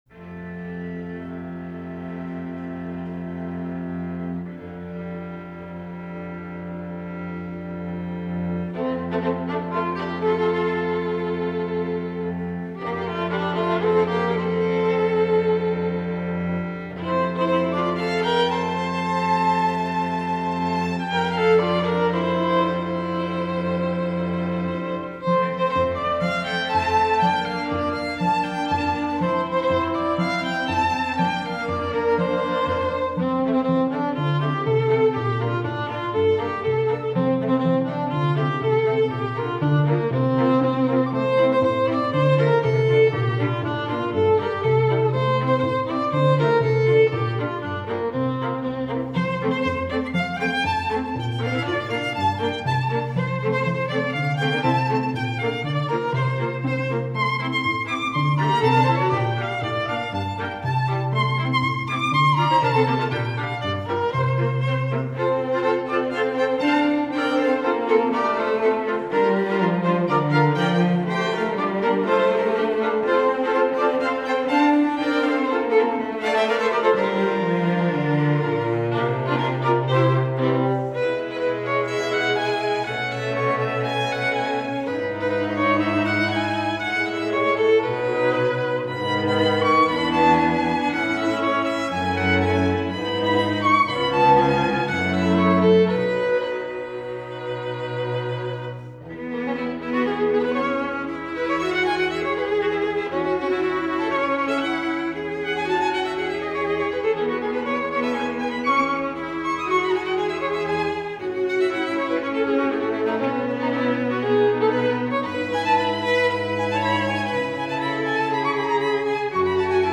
Voicing: 4 String